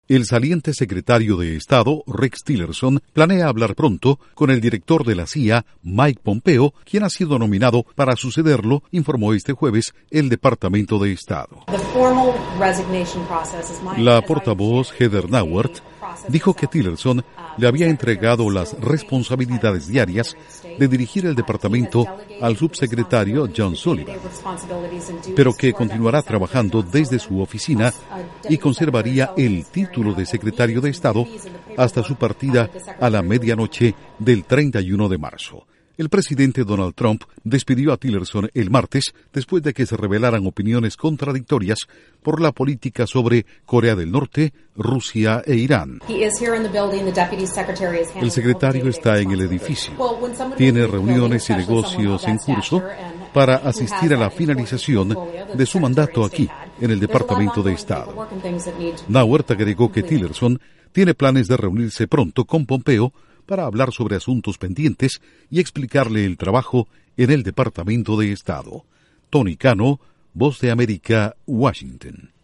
Incluye dos audios de Heather Nauert/ Vocera del Departamento de Estado.